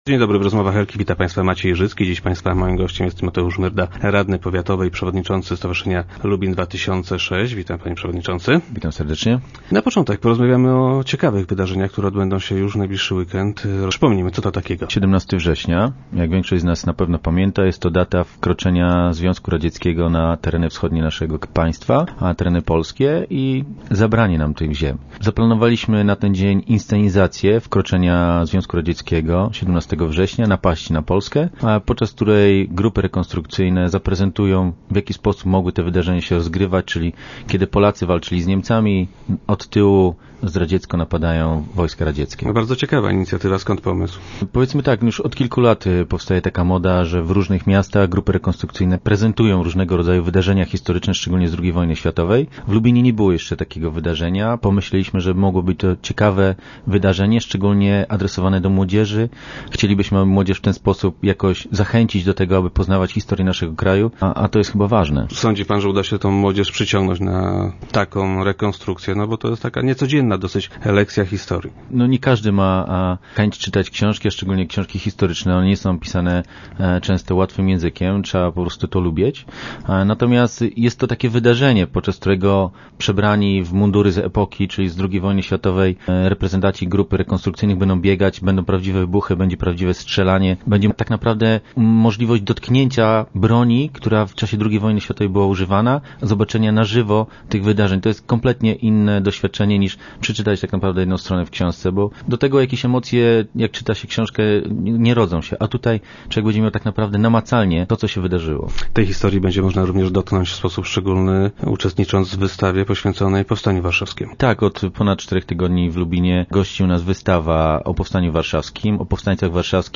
Myrda był gościem Rozmów Elki.